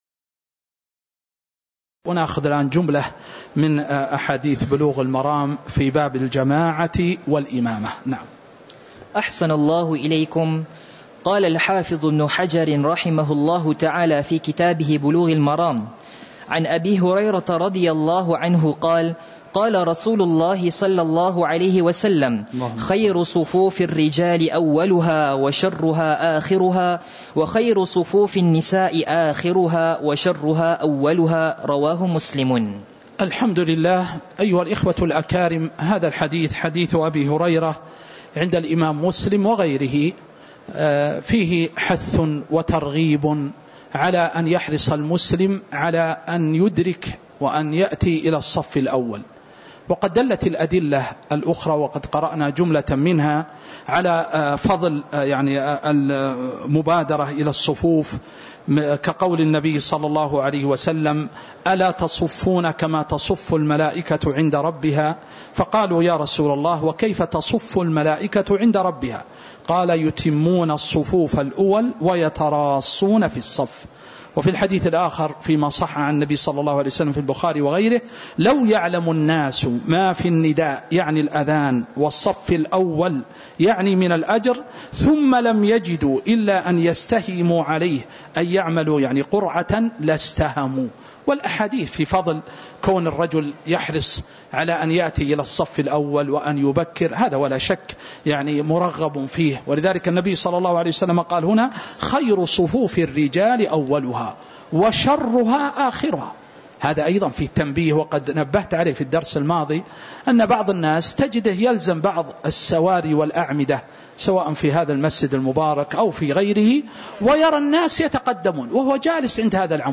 تاريخ النشر ١٧ جمادى الآخرة ١٤٤٥ هـ المكان: المسجد النبوي الشيخ